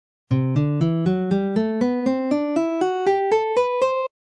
Along with Ionian, the Lydian mode has a major tonality and is essentially a major scale with an augmented fourth interval.
Notation formula (key of C) C-D-E-F-G-A-B-C/1
C Lydian scale pattern #4
c-lydian-mode-scale-pattern-4.mp3